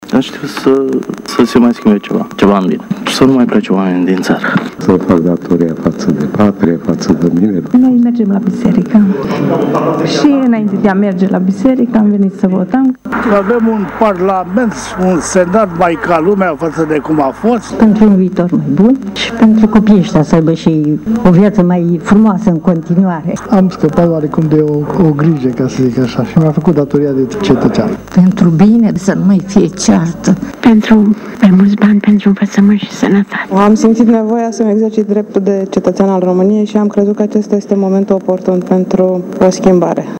Brașovenii au venit la vot pentru a-și face datoria, ca tinerii să trăiască mai bine si să nu mai plece în străinătate: